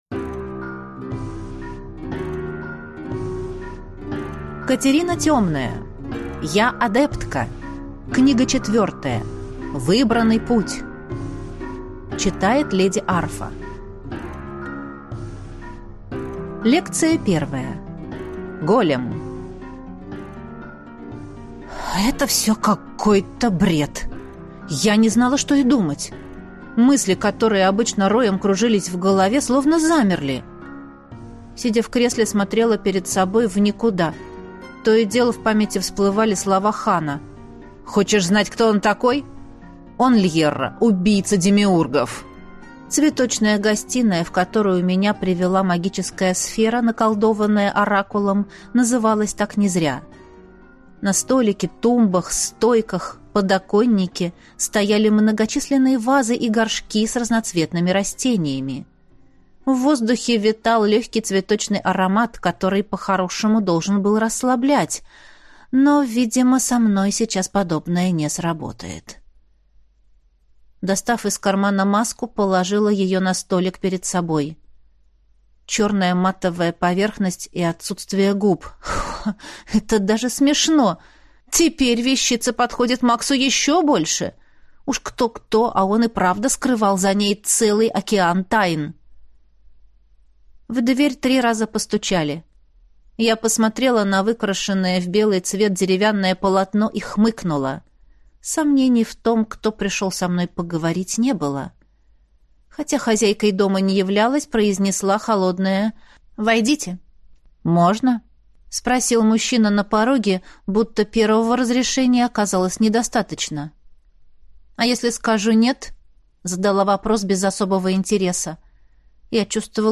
Аудиокнига Я – адептка. Книга 4. Выбранный путь | Библиотека аудиокниг